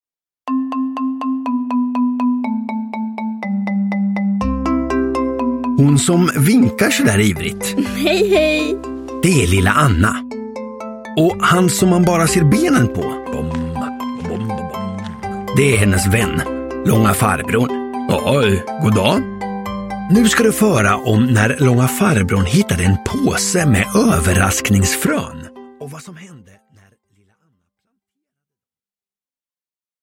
Lilla Anna och de mystiska fröna – Ljudbok – Laddas ner